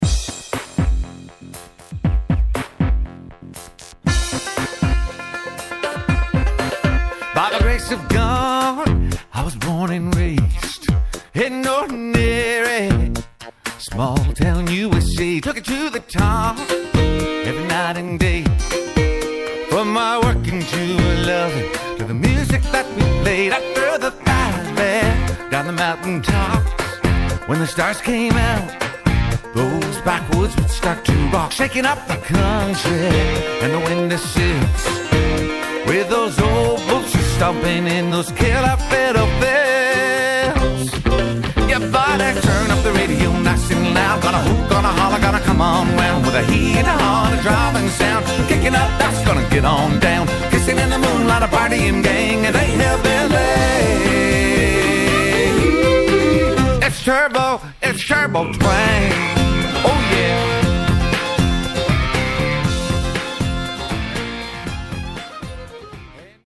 country dance hit